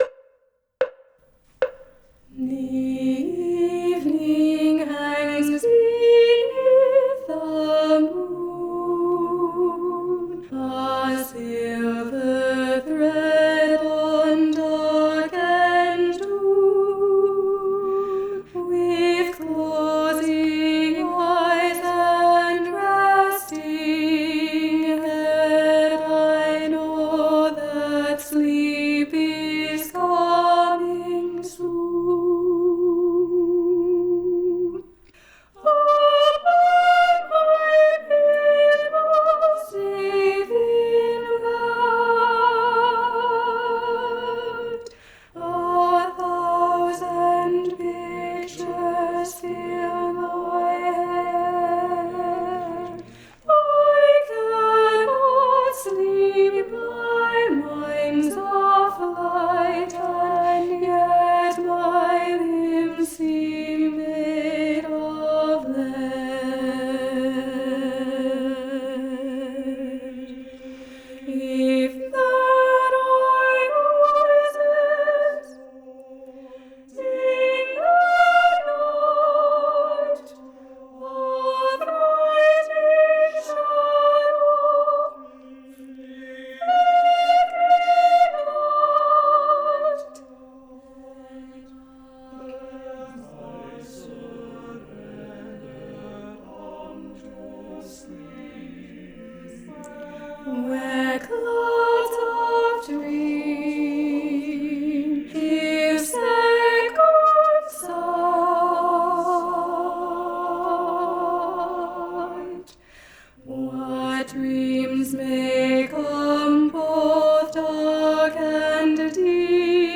- Œuvre pour chœur à 8 voix mixtes (SSAATTBB)
Soprano 1 Live Vocal Practice Track